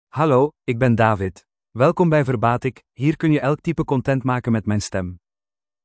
MaleDutch (Belgium)
David is a male AI voice for Dutch (Belgium).
Voice sample
David delivers clear pronunciation with authentic Belgium Dutch intonation, making your content sound professionally produced.